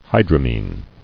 [hy·dra·mine]